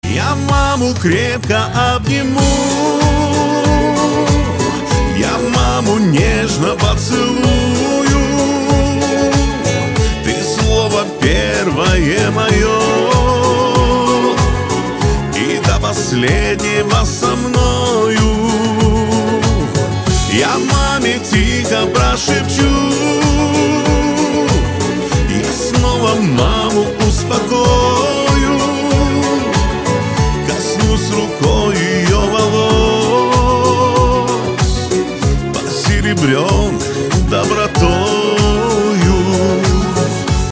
• Качество: 320, Stereo
душевные
спокойные
русский шансон
нежные